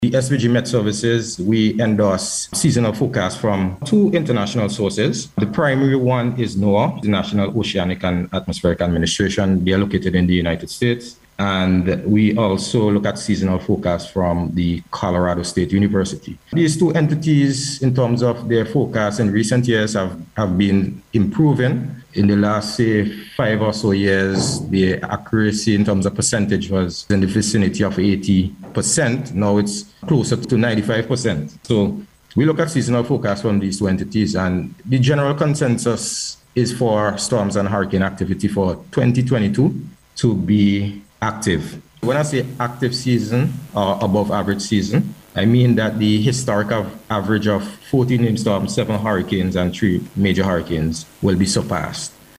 Speaking on NBC’s Face to Face program this morning